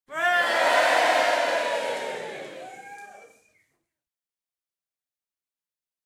cheer.ogg